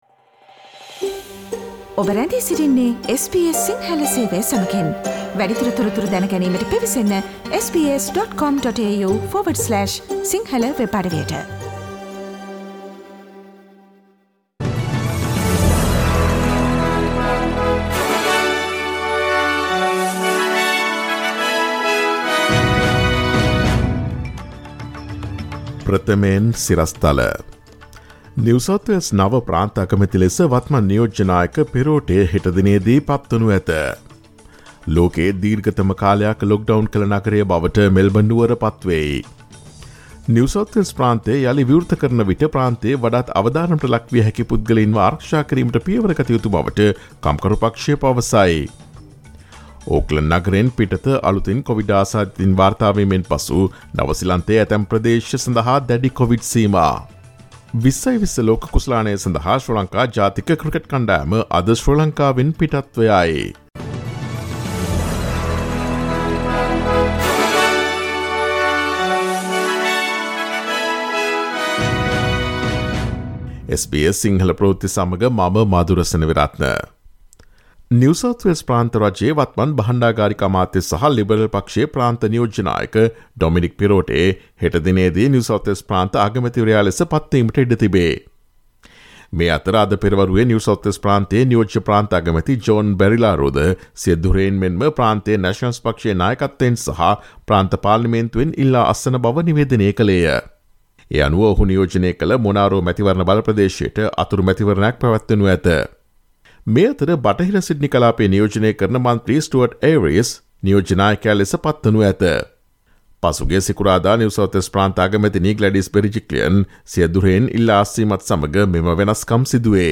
ඔස්ට්‍රේලියාවේ නවතම පුවත් මෙන්ම විදෙස් පුවත් සහ ක්‍රීඩා පුවත් රැගත් SBS සිංහල සේවයේ 2021 ඔක්තෝබර් 04 වන දා සඳුදා වැඩසටහනේ ප්‍රවෘත්ති ප්‍රකාශයට සවන් දීමට ඉහත ඡායාරූපය මත ඇති speaker සලකුණ මත click කරන්න.